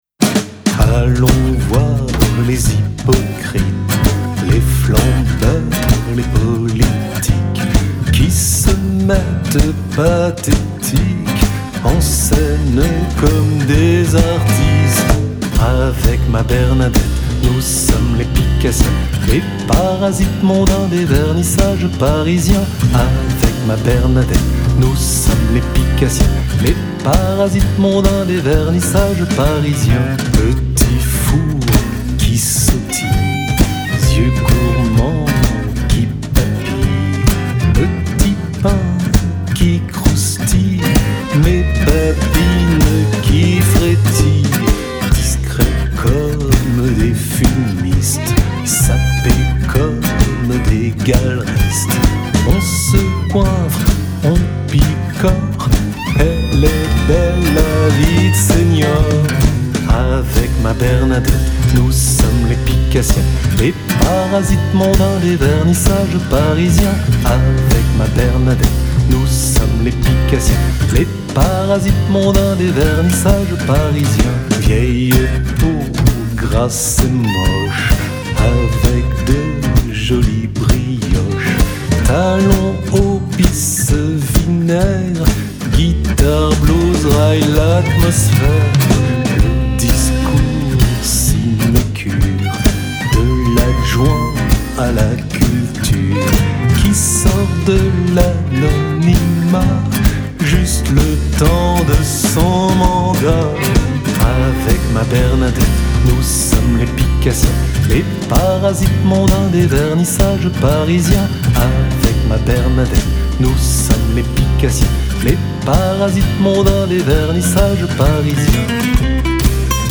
Guitares
Contrebasse
Violon
Batterie